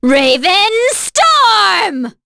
Maria-Vox_Skill1_c.wav